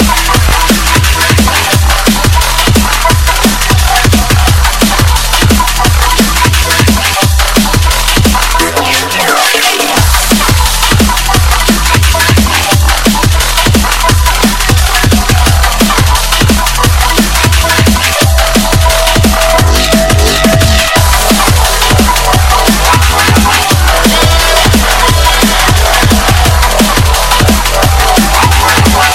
TOP >Vinyl >Drum & Bass / Jungle
TOP > HARD / TECH